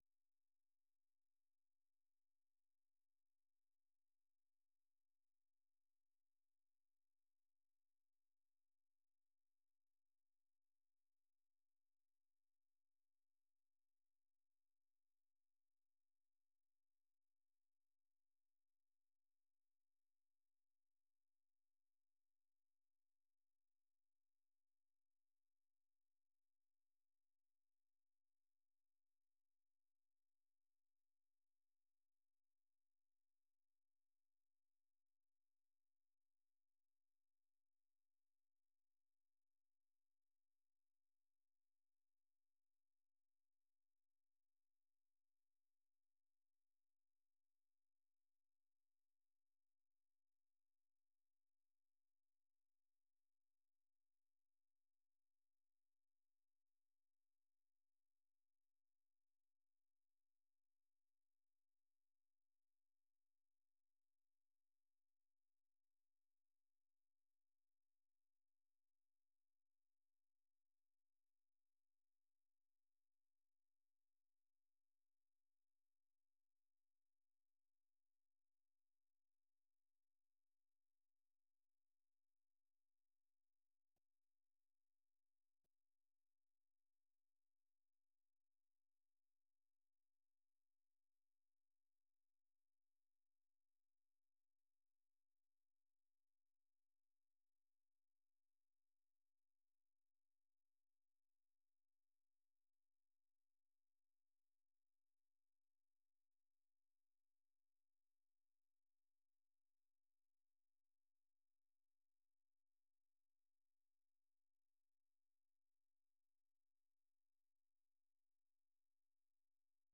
세계 뉴스와 함께 미국의 모든 것을 소개하는 '생방송 여기는 워싱턴입니다', 아침 방송입니다.